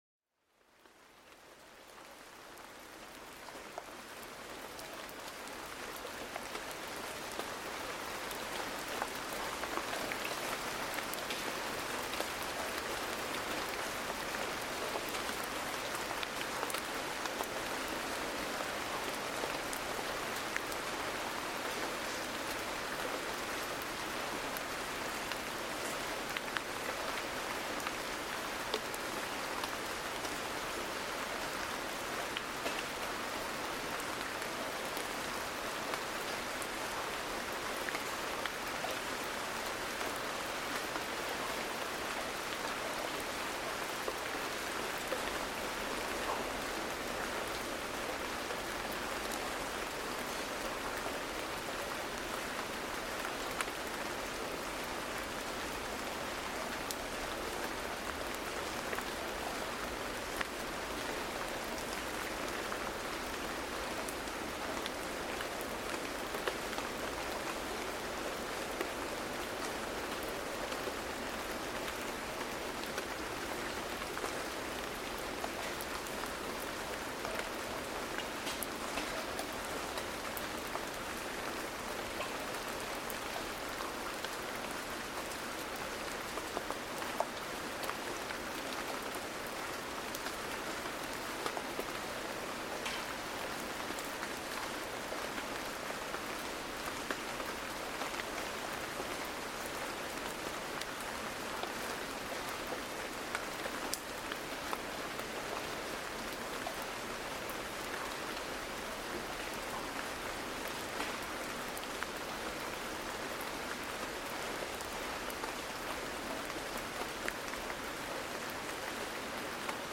En este episodio, déjate llevar por el suave sonido de la lluvia cayendo delicadamente sobre las hojas, un verdadero bálsamo para el alma. Descubre cómo este sonido natural ayuda a reducir el estrés y favorece un sueño profundo y reparador.